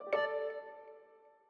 Longhorn Ten Alfa - Message Nudge.wav